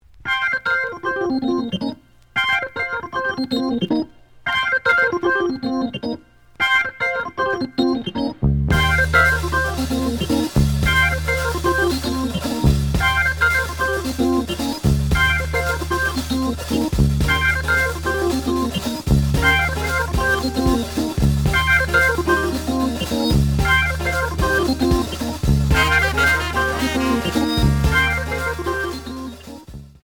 The audio sample is recorded from the actual item.
●Genre: Jazz Funk / Soul Jazz
Slight edge warp. But doesn't affect playing. Plays good.